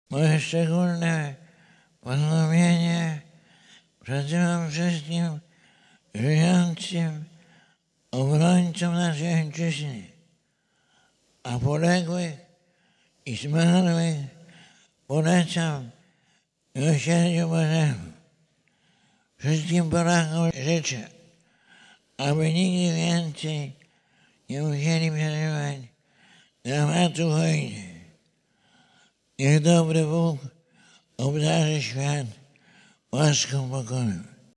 W słowie do rodaków Jan Paweł II przypomniał przypadającą dziś 65. rocznicę napaści hitlerowskiej na Polskę i rozpoczęcia II wojny światowej: